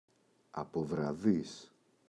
αποβραδίς [apovra’ðis]